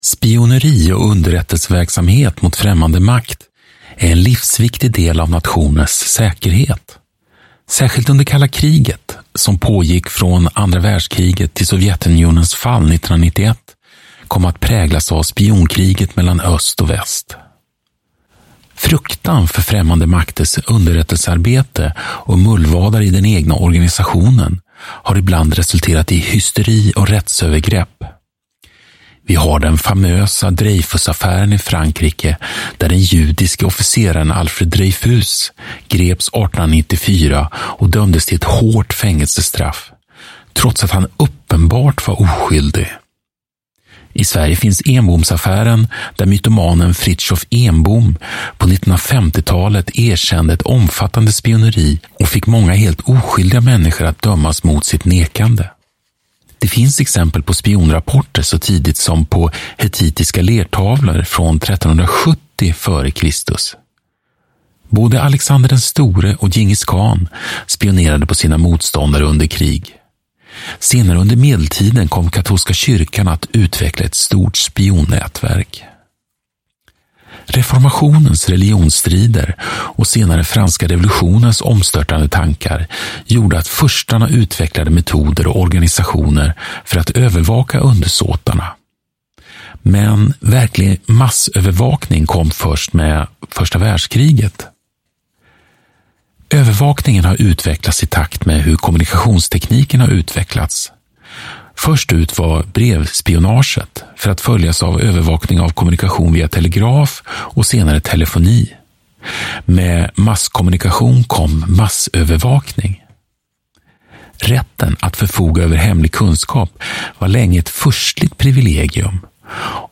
Historia Nu: Spioner och hemliga underrättelser (ljudbok) av -